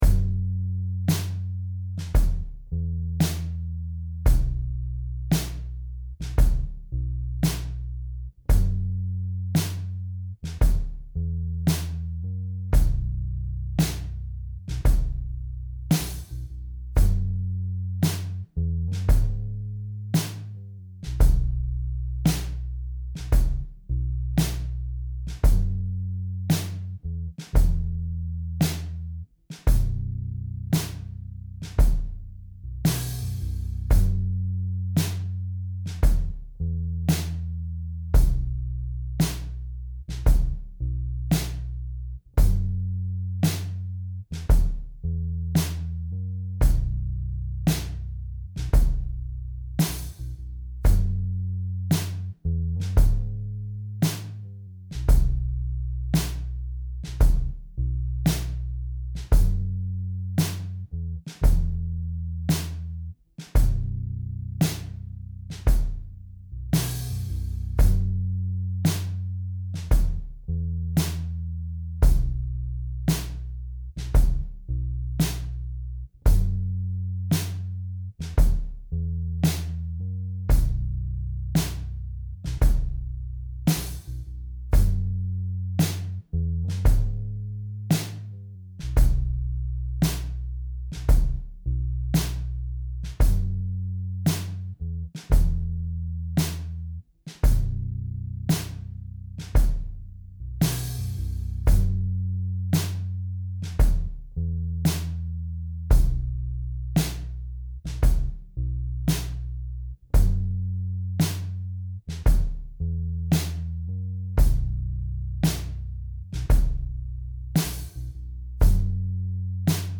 F#mmi, E, B. Slow and steady.